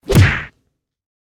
kick.ogg